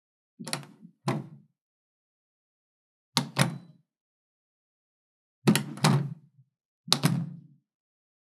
95.ドアの開閉【無料効果音】
ドア効果音